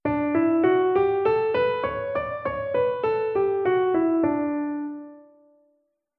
Paradiddle Kapitel 1 → D-Dur-Tonleiter - Musikschule »allégro«
TonleiternDDur.mp3